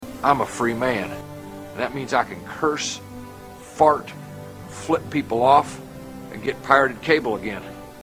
Category: Comedians   Right: Personal
Tags: You're Welcome America Will Ferrell George Bush Will Ferrell George Bush impersonation Will Ferrell George Bush